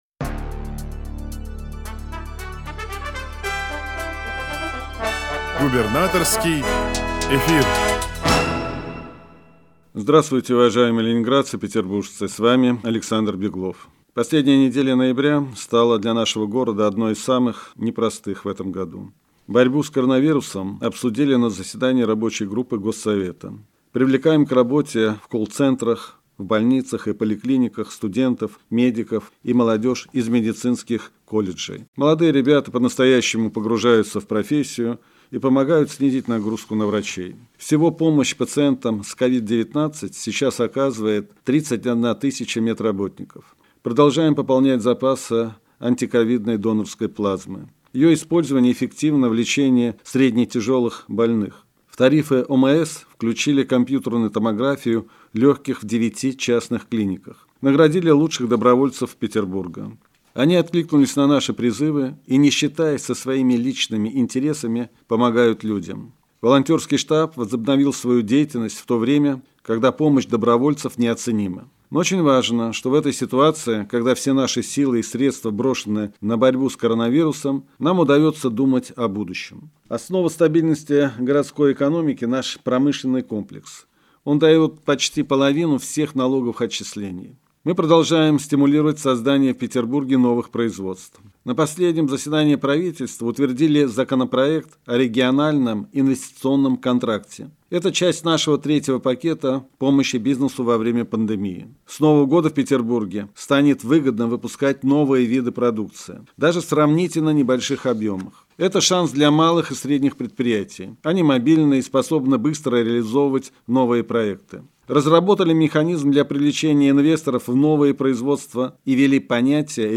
Губернаторский эфир